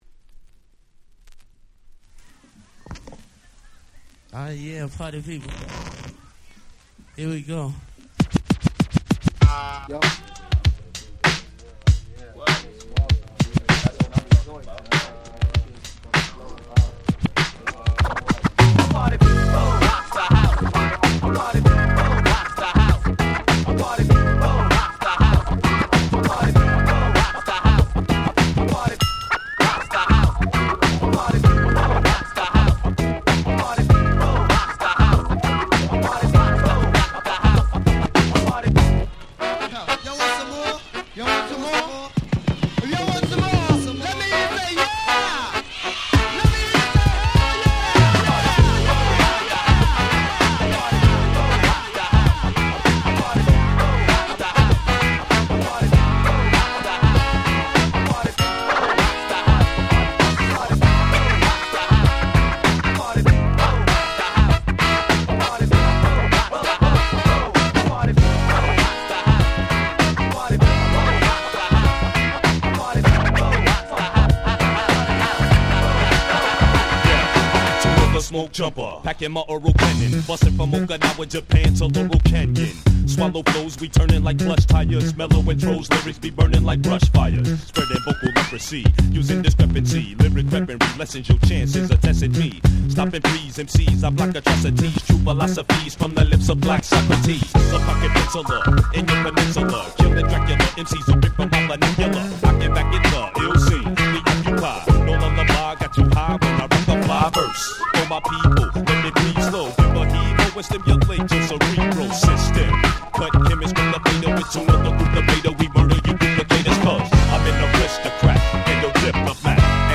99' Underground Hip Hop Classics !!